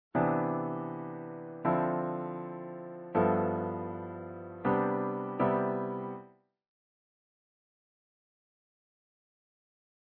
Those last few chords are a bit thin yes? Here's some thicker sounding chords, one of each of the three chord types featuring the 9th color tone.